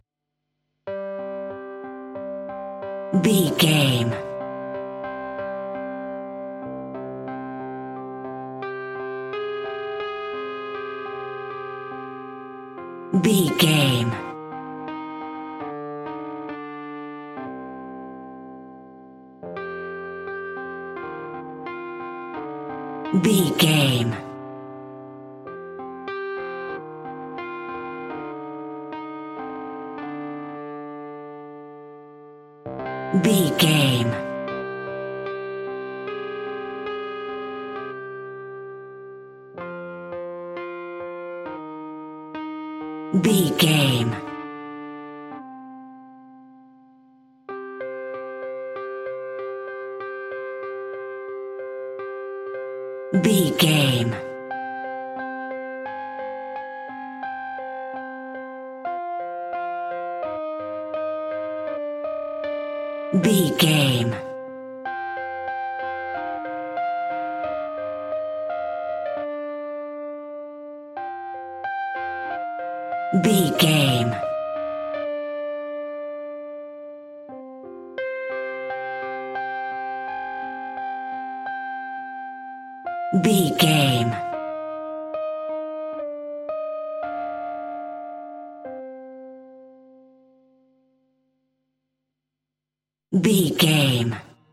Aeolian/Minor
scary
ominous
dark
haunting
eerie
electric guitar
horror
synth
keyboards
pads